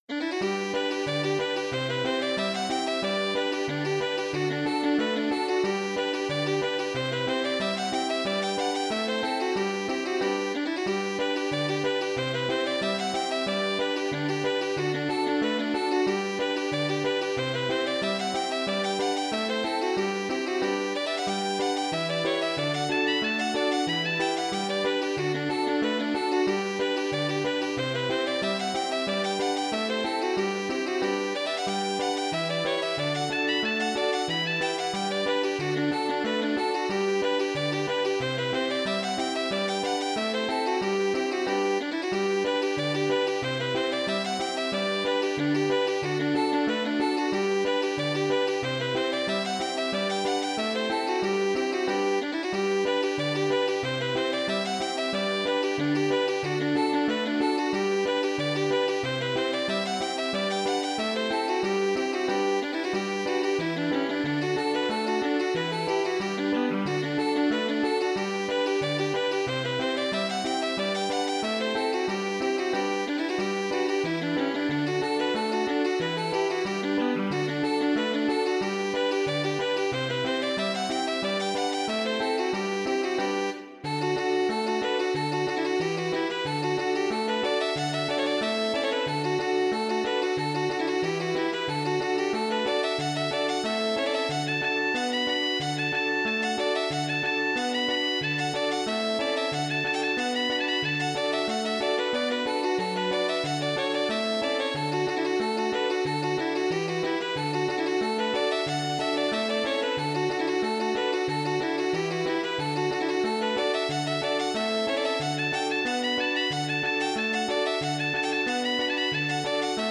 reels